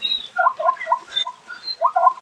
sounds_zebra.ogg